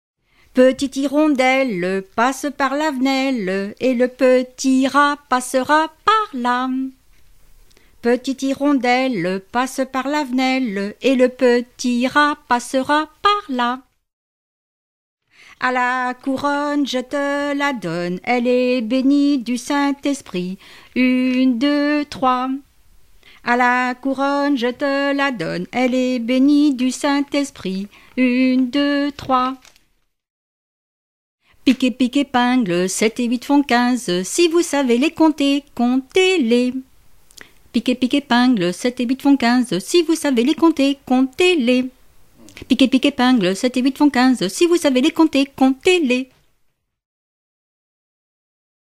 formulette enfantine
Fonds Arexcpo en Vendée